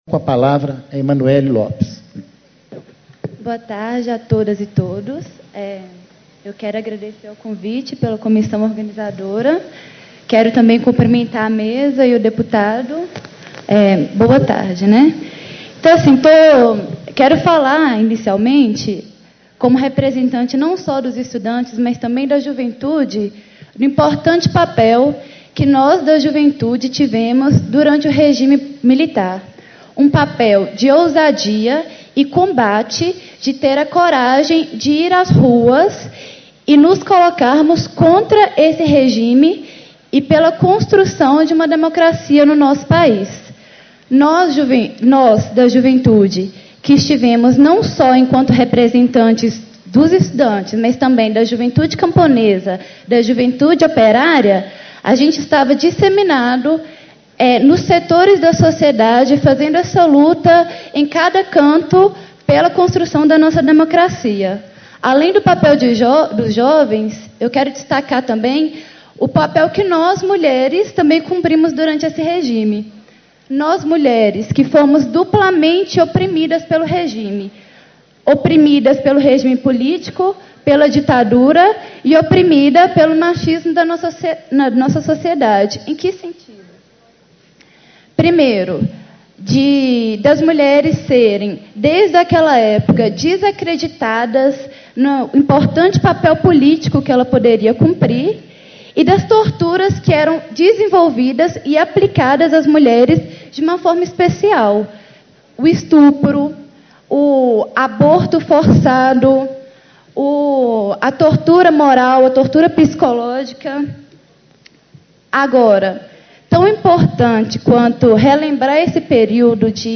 Discursos e Palestras